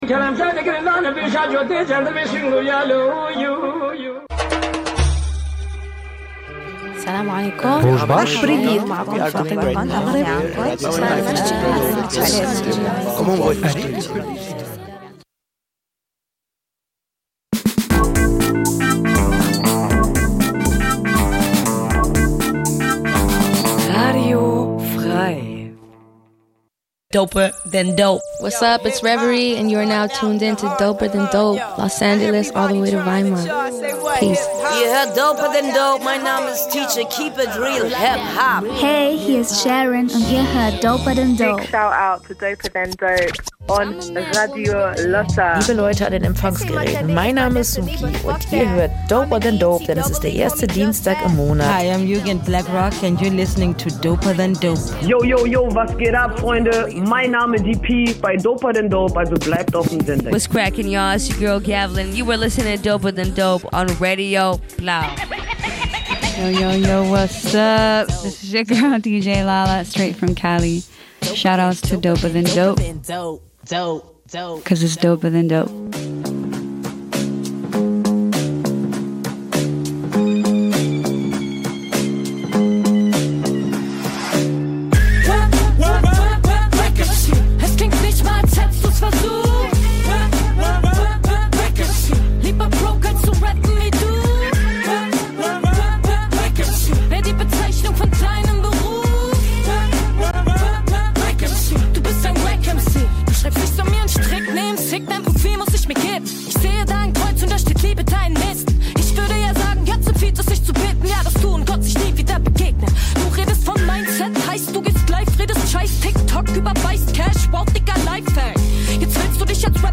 Dabei werden auch Entwicklungen und wichtige Releases im HipHop Mainstream angeschaut, der Fokus liegt aber vor allem auf Sub-Szenen und Artists, die oft zu wenig Beachtung finden, wie Frauen und queere Rapper*innen.